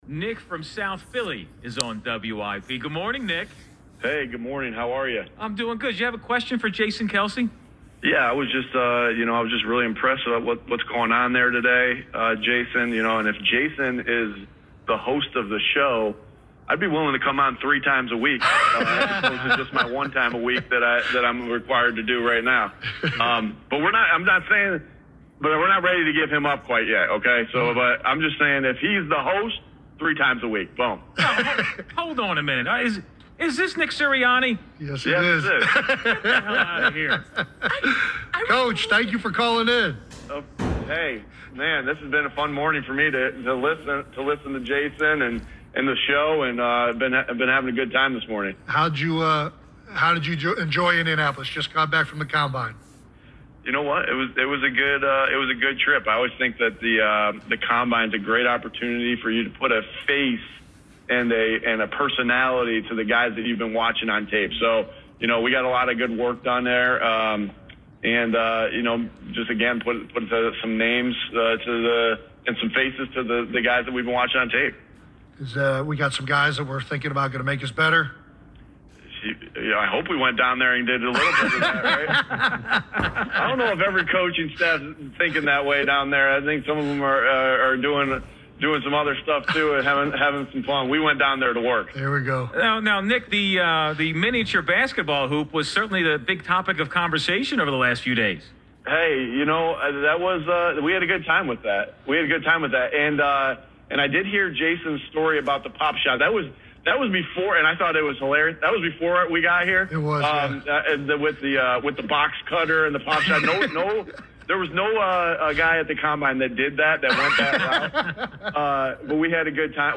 Jason Kelce was the surprise mystery guest filling in for Angelo Cataldi Wednesday on WIP. He got a call around 9 a.m. from a “Nick in South Philly,” doing his best upstate New York impression: